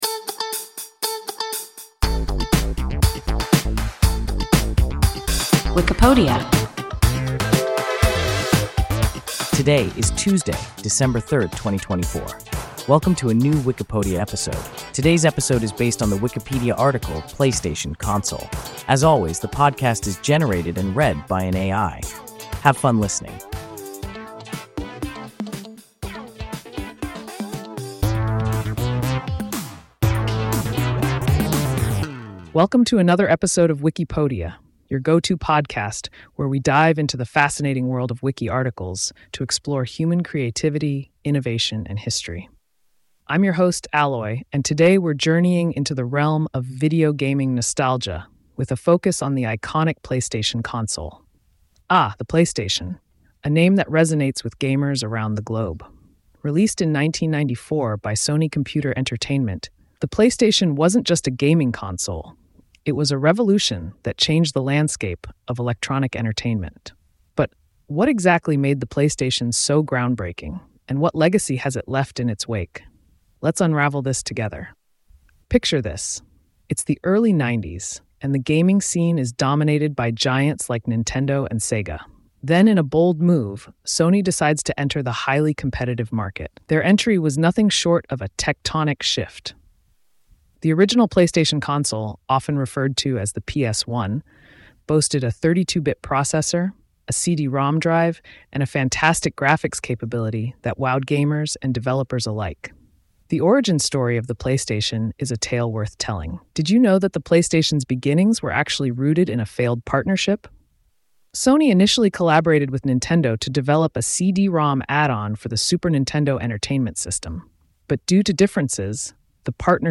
PlayStation (console) – WIKIPODIA – ein KI Podcast